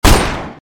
/ Assets / ShotSimulator / Resources / Audio / 音效 / 射击短.mp3 射击短.mp3 37 KB History Raw View Raw
射击短.mp3